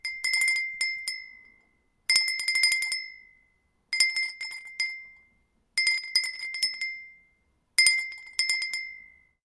На этой странице собраны звуки коровьего колокольчика — натуральные и атмосферные записи, которые перенесут вас на деревенское пастбище.
Звон колокольчика на корове